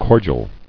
[cor·dial]